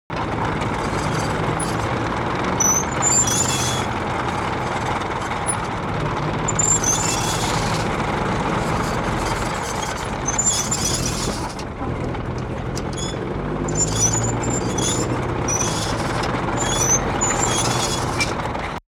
ruido_cadenas_tanque.wav